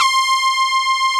Index of /90_sSampleCDs/Club-50 - Foundations Roland/SYN_xAna Syns 1/SYN_xJX Brass X2